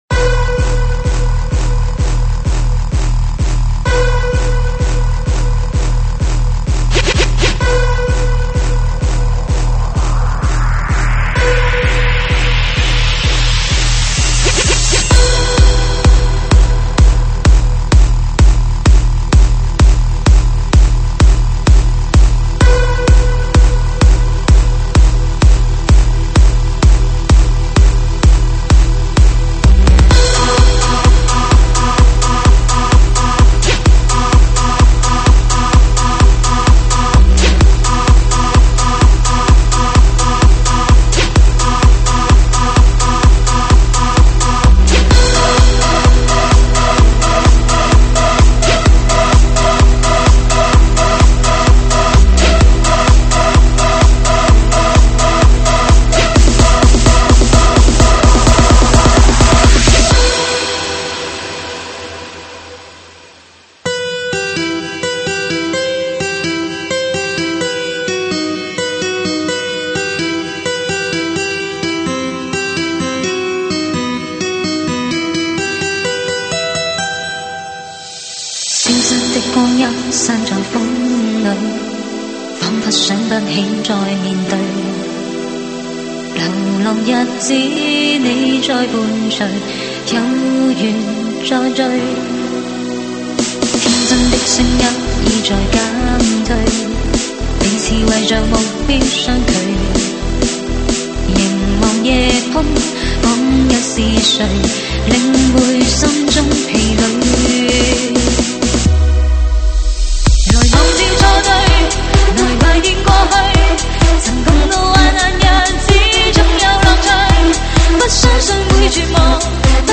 舞曲类别：慢摇舞曲